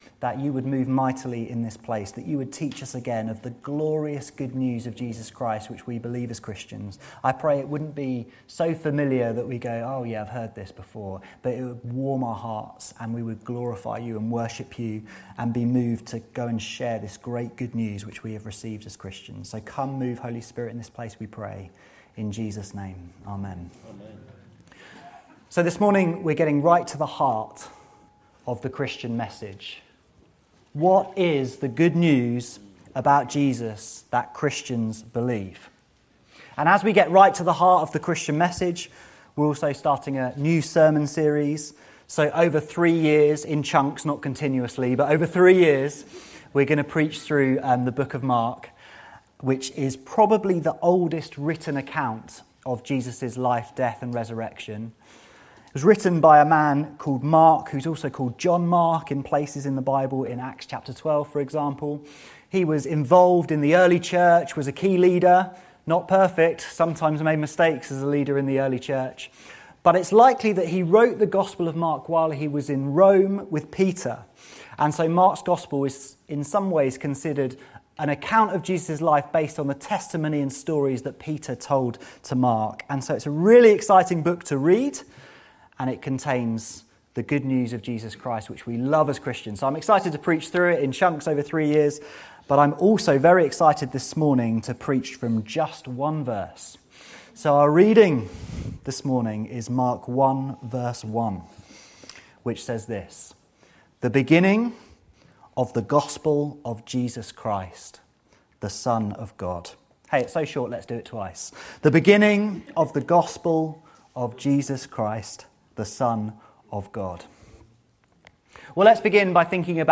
This sermon is a theological proclamation of what Jesus’ life means for us and unpacks why this is extraordinarily good news for everyone who believes in him.